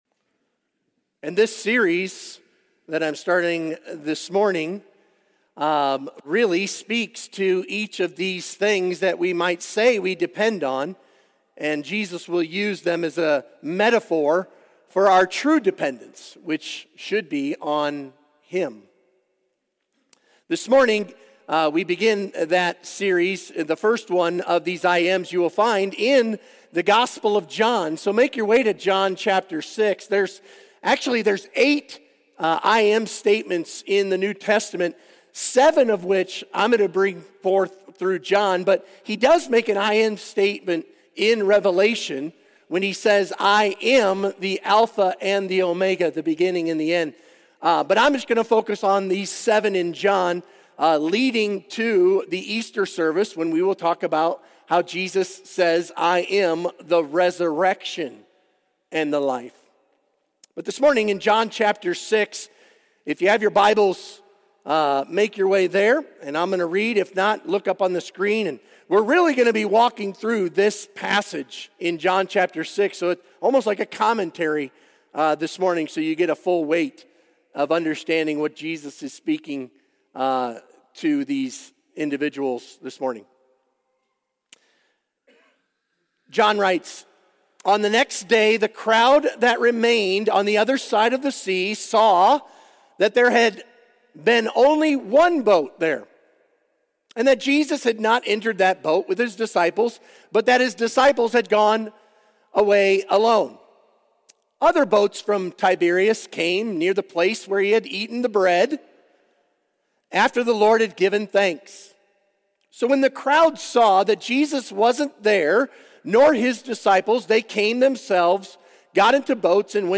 Sermon Questions Read John 6:22-69.